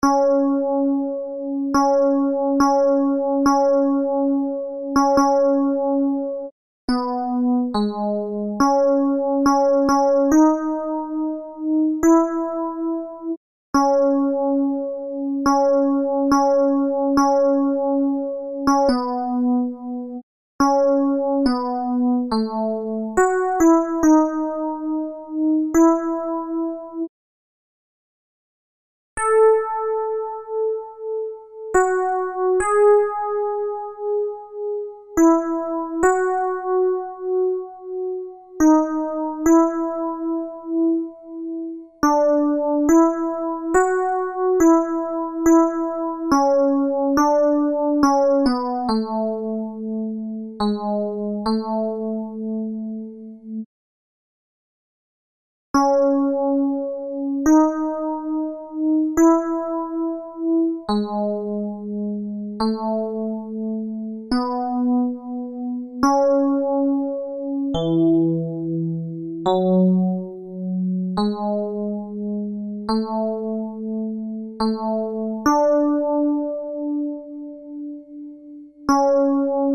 Ténors
brahma_tenors.MP3